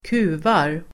Uttal: [²k'u:var]